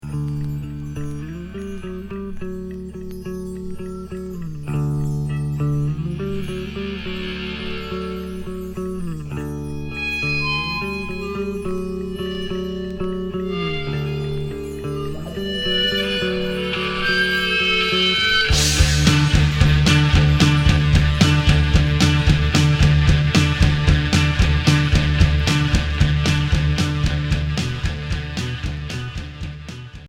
Noisy pop Unique 45t